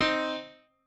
piano4_4.ogg